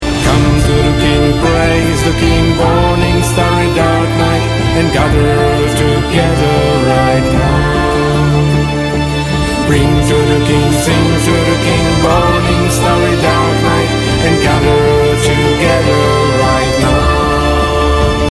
Ženský hlas / voiceover / vokály
Pracuji na svém mikrofonu, ale i ve vašem studiu!